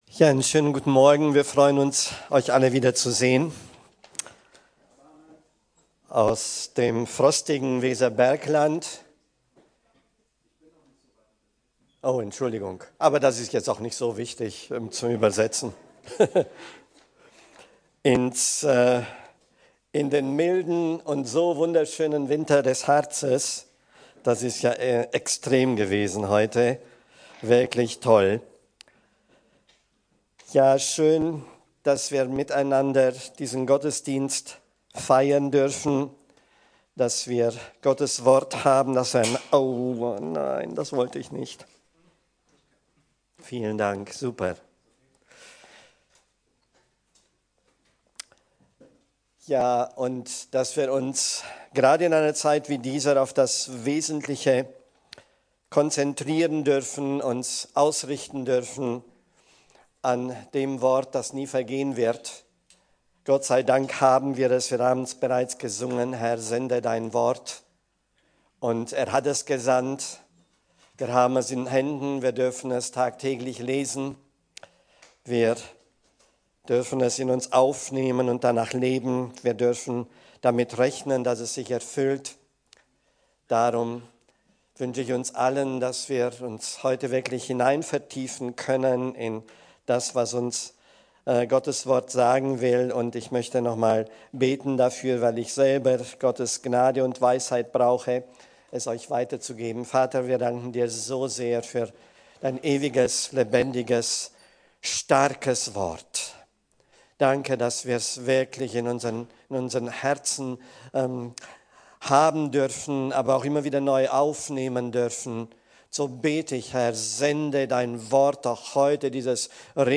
Kirche am Ostbahnhof, Am Ostbahnhof 1, 38678 Clausthal-Zellerfeld, Mitglied im Bund Freikirchlicher Pfingstgemeinden KdöR
Predigt